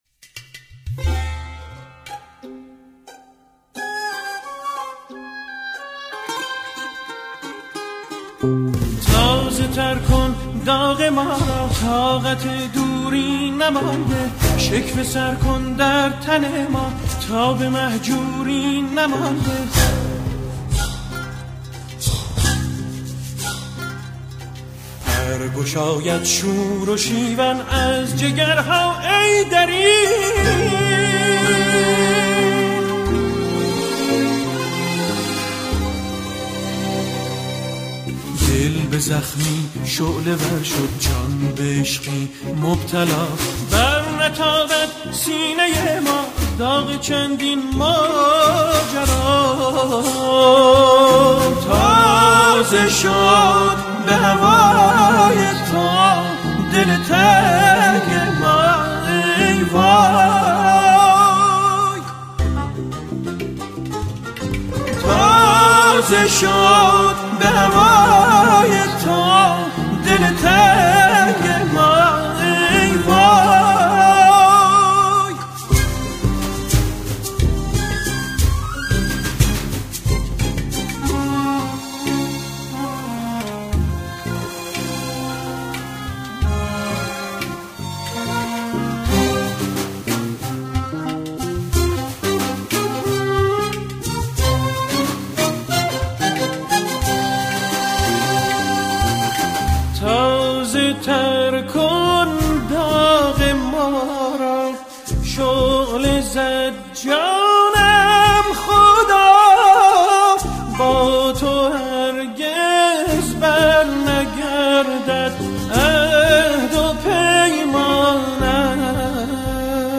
تسنیف